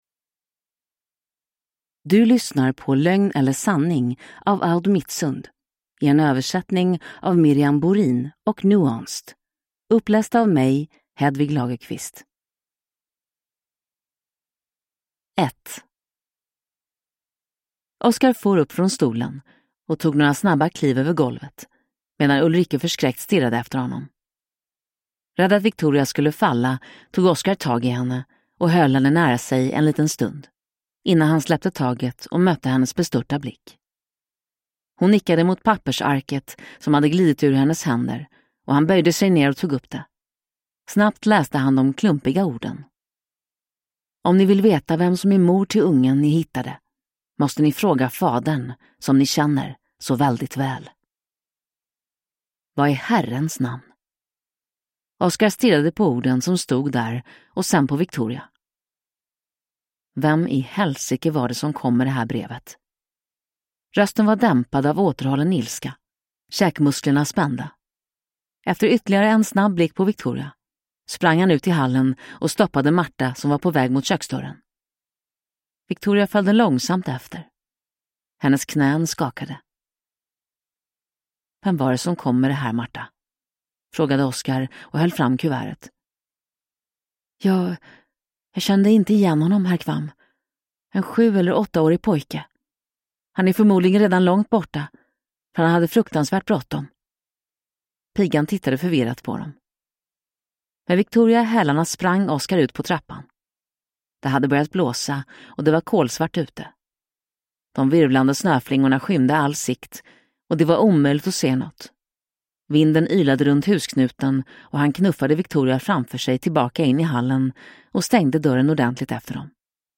Lögn eller sanning – Ljudbok